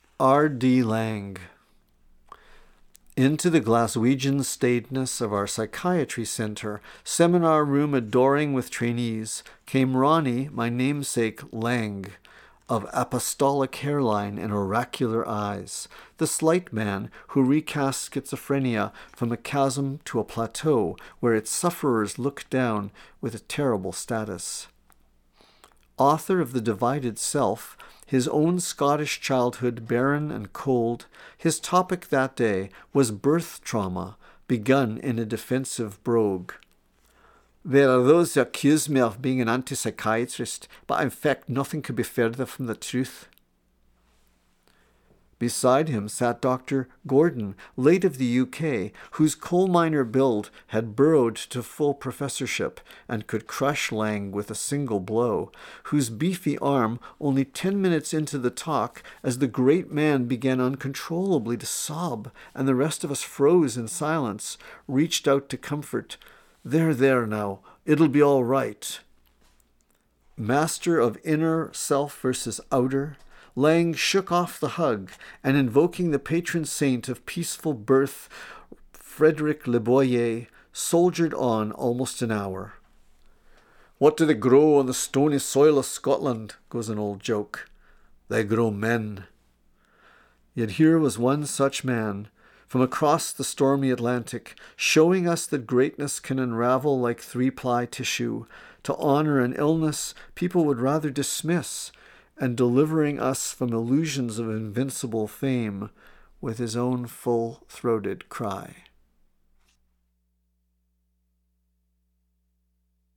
Poetry
reading 'R.D. Laing' from 'Dungenessque' (1:53)